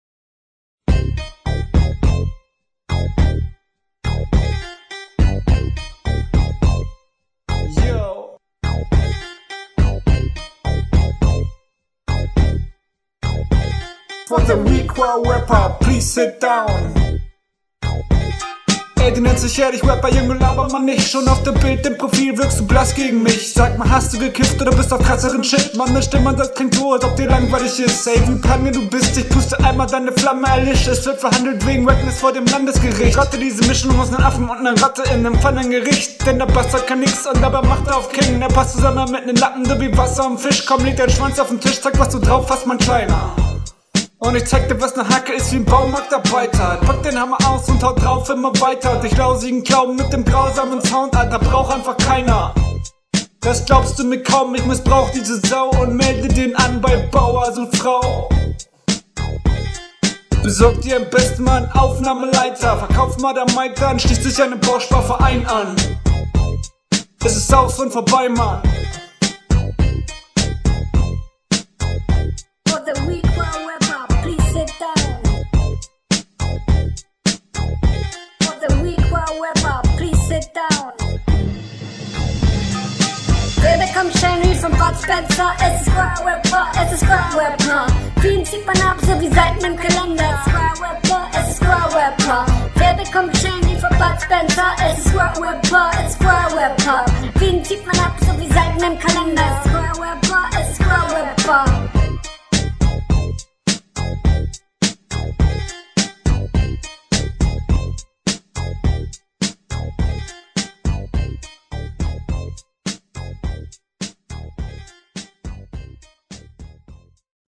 kannst du das mit dem paning bitte lassen
musstest du den beat wirklich picken? flow cooler als in der rr1 aber man merkt …
der pitch is super nervig. aber an sich ne ok-e runde, aber pick doch nich …